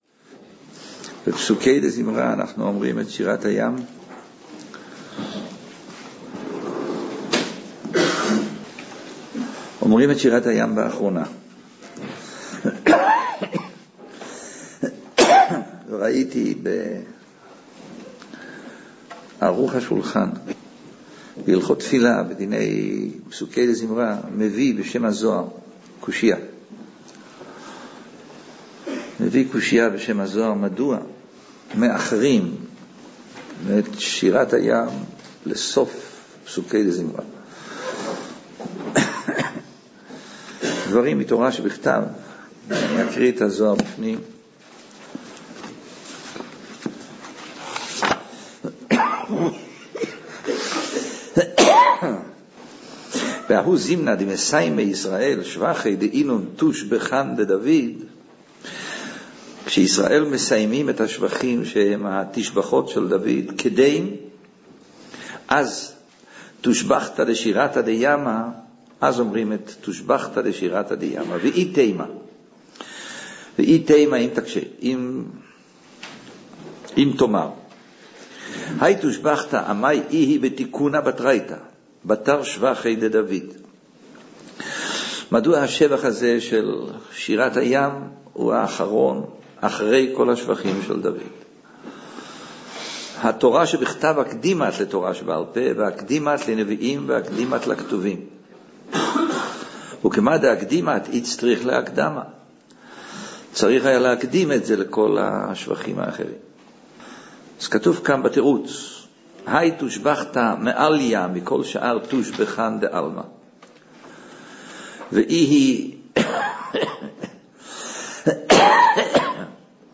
Бешалах. Шират а-Ям – Урок